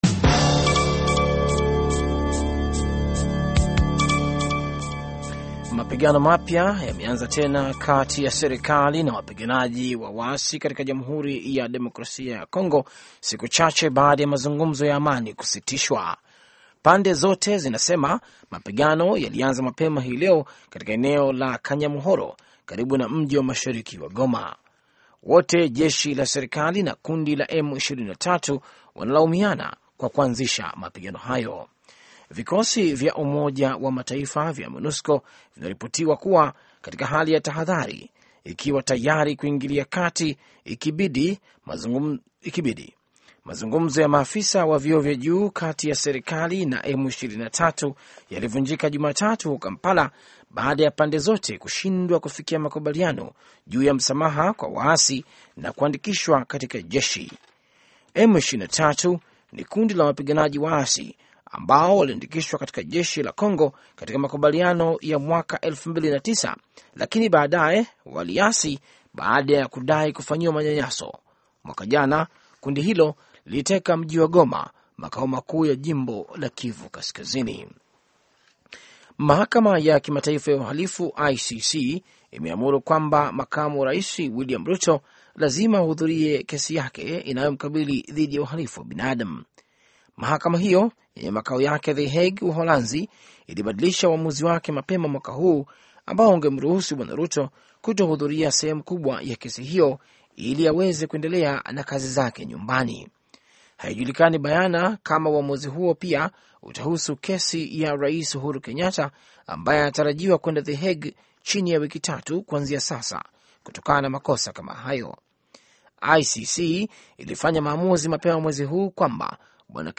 Taarifa ya Habari VOA Swahili - 4:55